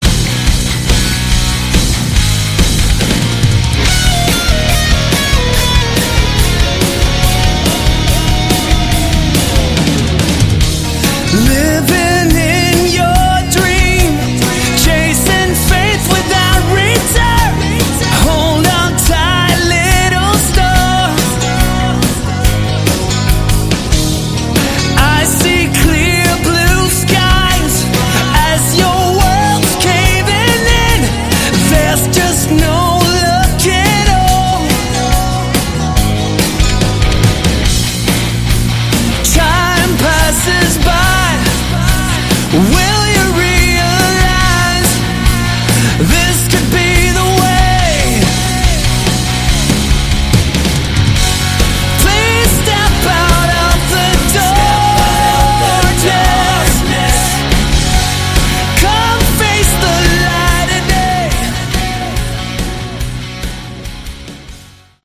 Category: Melodic Rock
vocals, guitar